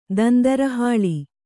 ♪ dandara hāḷi